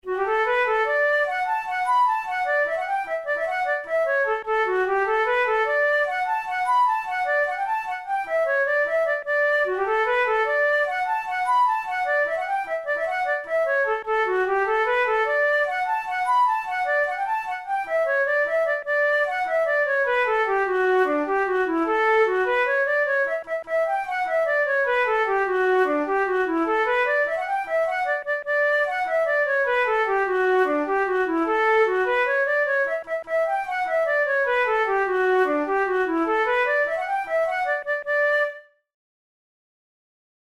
KeyD major
Time signature6/8
Tempo100 BPM
Jigs, Traditional/Folk
Traditional Irish jig